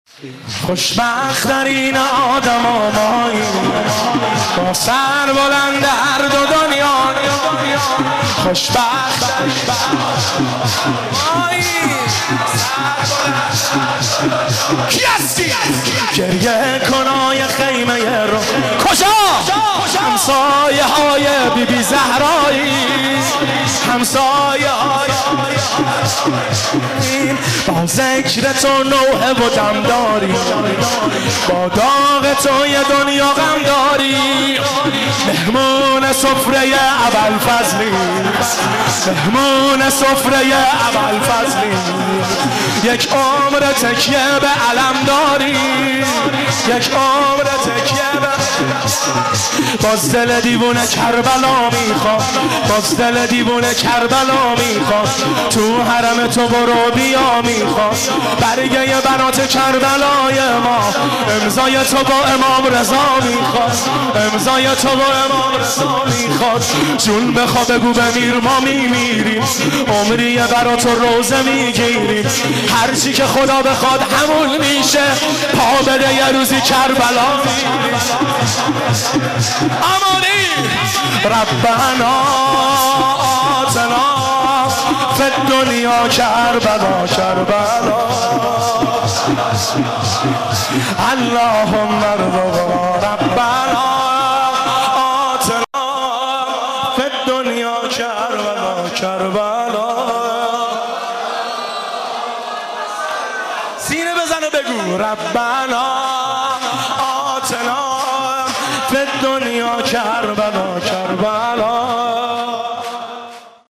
که در هیئت بین الحرمین تهران اجرا شده است
دلم رو بردی ، غصه مو خوردی ، اشکمو در اودری کربلا نبردی ( شور )
جلسه هفتگی